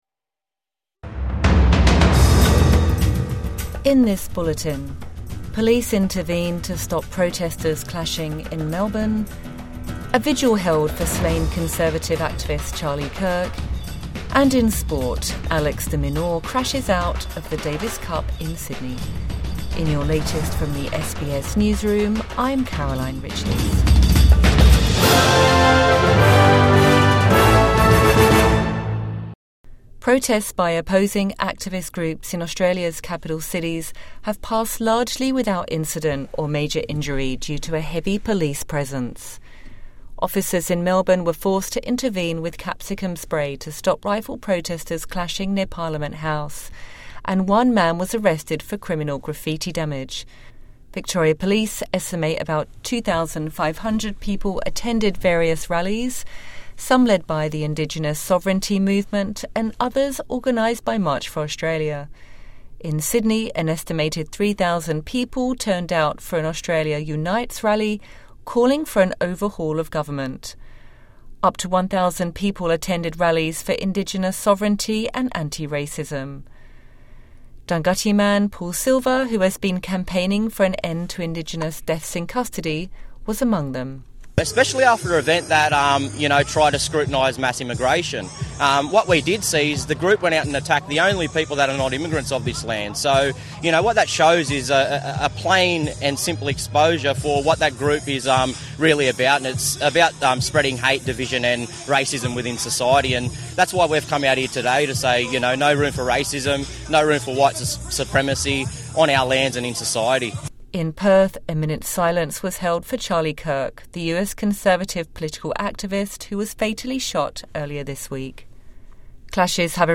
Competing protests in Australia's major cities | Morning News Bulletin 14 September 2025